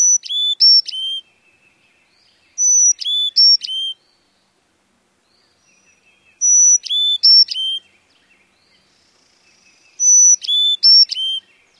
卡罗山雀鸣声